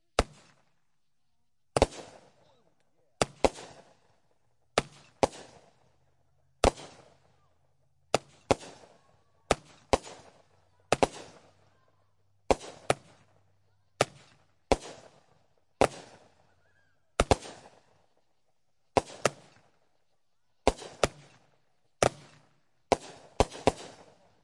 Fireworks » Fireworks15
描述：Fireworks recorded using a combination of Tascam DR05 onboard mics and Tascam DR60 using a stereo pair of lavalier mics and a Sennheiser MD421. I removed some voices with Izotope RX 5, then added some low punch and high crispness with EQ.
标签： whiz fireworks bang crackle